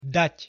pronunciation_sk_dat.mp3